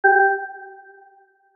error_sound.mp3